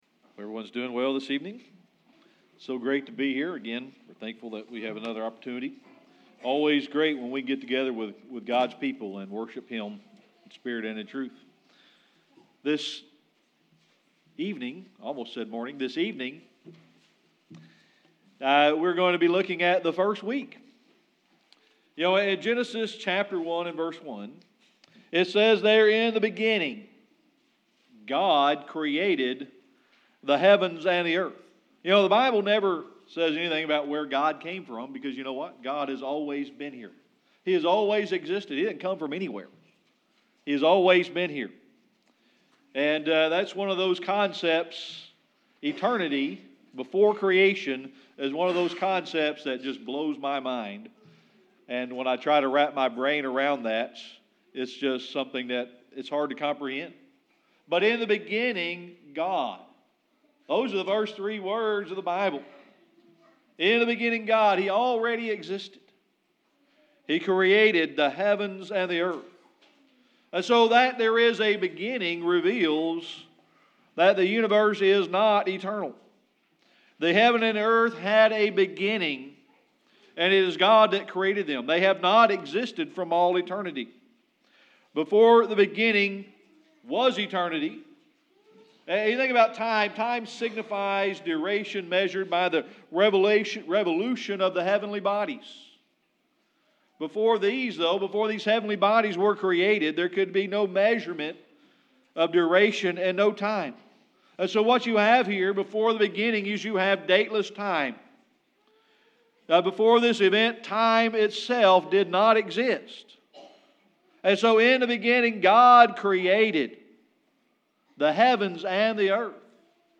Series: Sermon Archives
Genesis 1 Service Type: Sunday Evening Worship Tonight we're going to look at the first week.